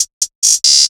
Closed Hats